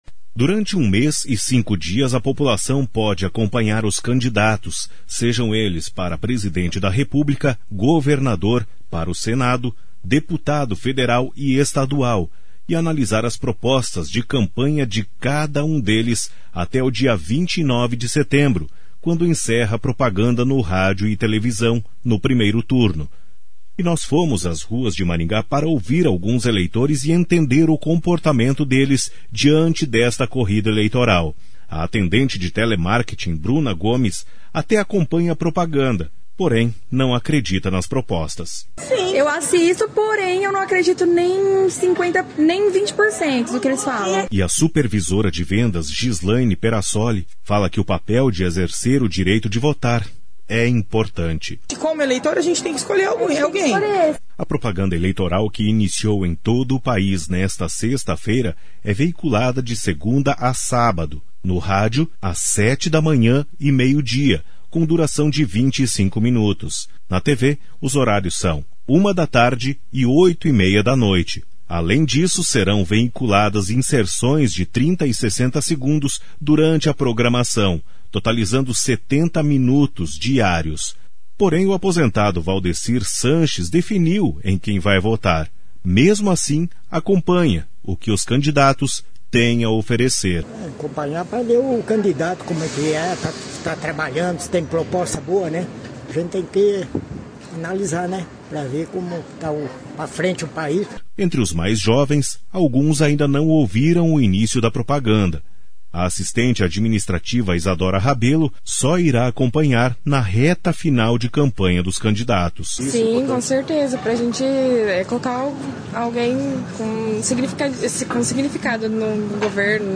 A CBN foi às ruas de Maringá para ouvir eleitores e saber qual é o comportamento deles diante desta corrida eleitoral.